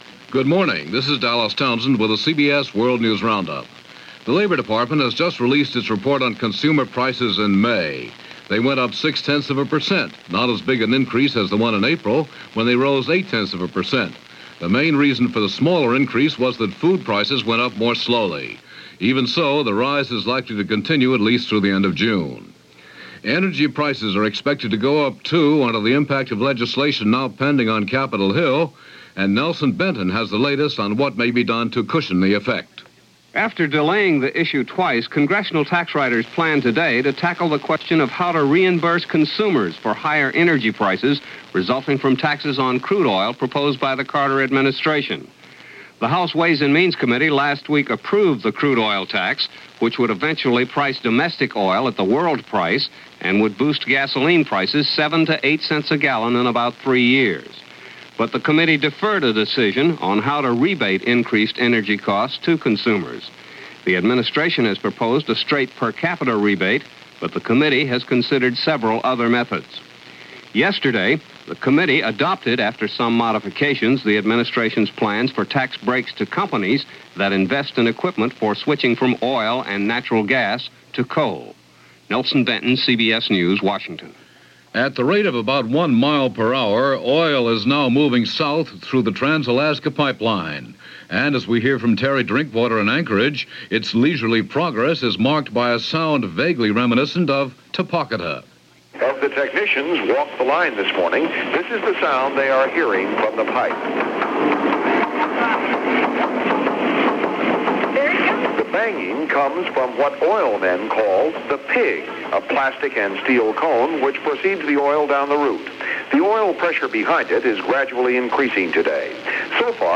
June 21, 1977 – CBS World News Roundup – Gordon Skene Sound Collection –